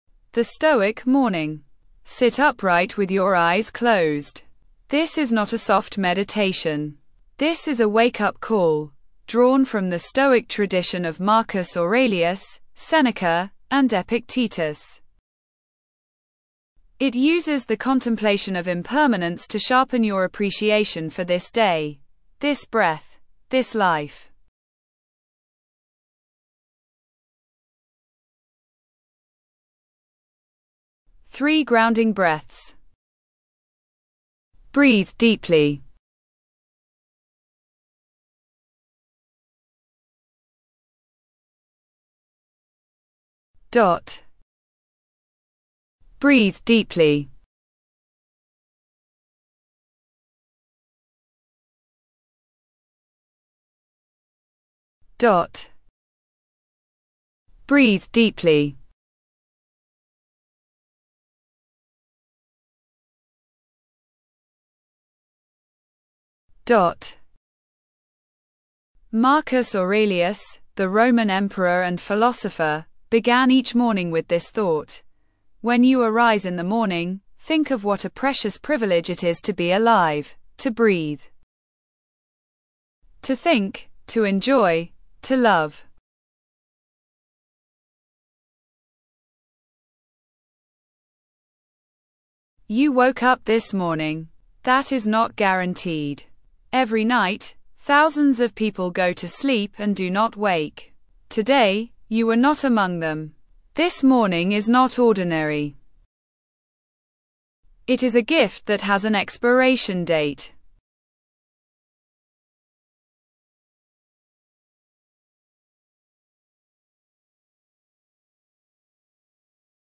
An advanced morning contemplation drawing from Stoic philosophy. Reflect on impermanence to ignite urgency, gratitude, and intentional living.